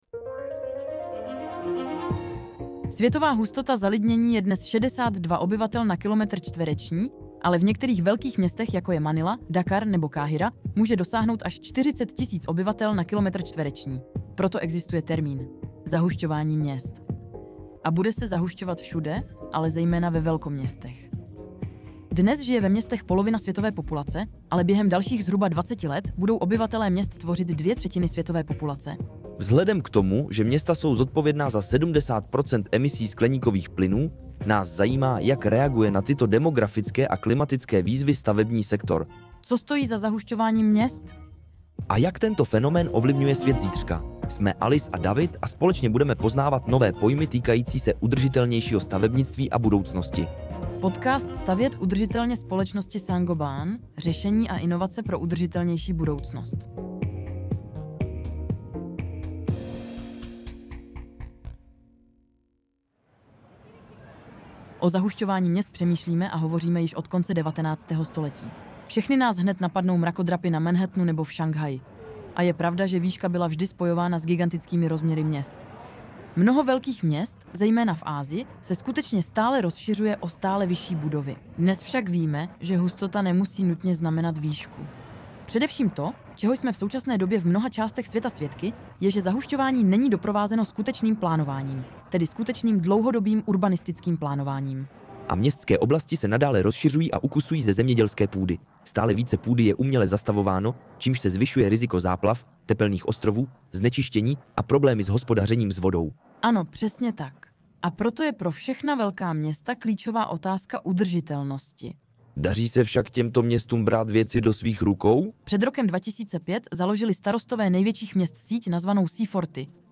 Města musí nutně přijmout nové stavební metody, aby se vypořádala s rostoucí urbanizací. 🌱 Tento podcast pro vás z francouzského originálu přeložila a také namluvila umělá inteligence, aby i ten byl ekologicky šetrný a pomáhal nám snižovat uhlíkovou stopu.